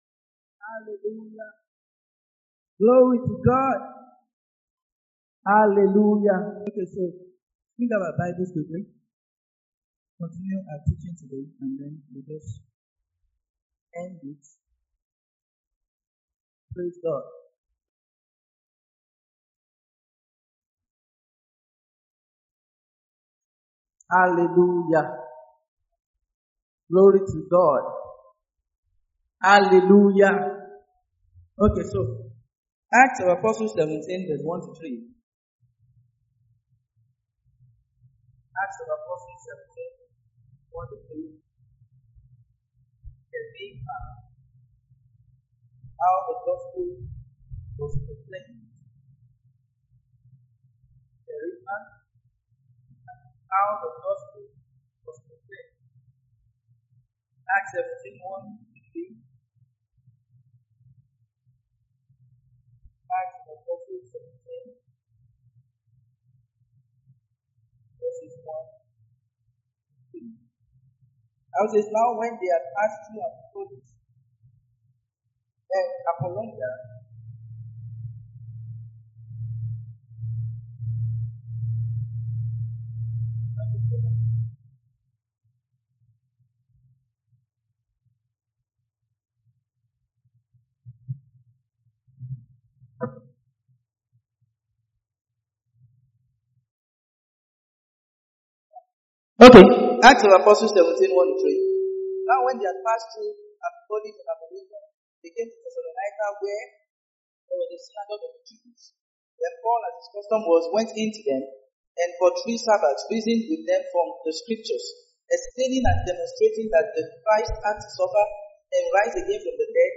Kerygma (How the Gospel was Proclaimed) - Part 6 - TSK Church, Lagos
Sunday Sermons